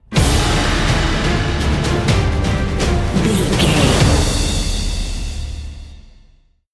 Fast paced
Aeolian/Minor
Fast
cinematic
driving
frantic
brass
drums
strings